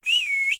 animalia_horse_whistle.ogg